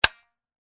Descarga de Sonidos mp3 Gratis: juguete 3.
juguetes-juguete-10-.mp3